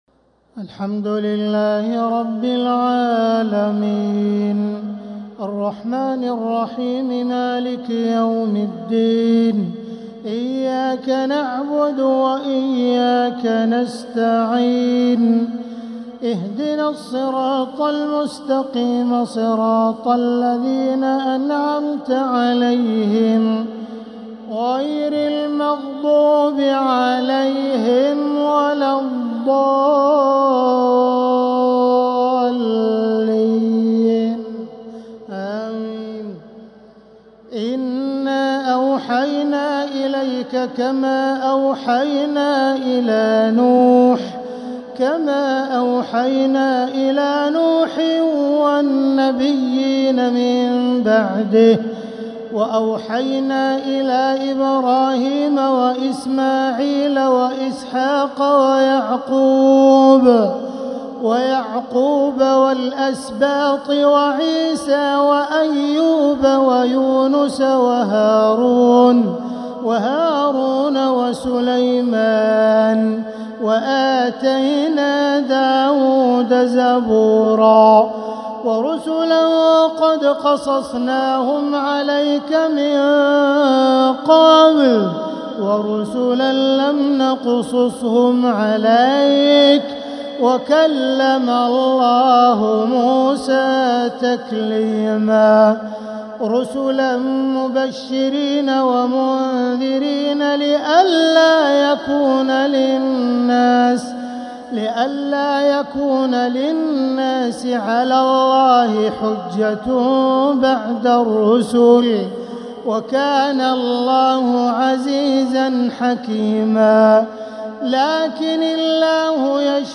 تراويح ليلة 7 رمضان 1446هـ من سورة النساء {163-176} Taraweeh 7th night Ramadan 1446H Surah An-Nisaa > تراويح الحرم المكي عام 1446 🕋 > التراويح - تلاوات الحرمين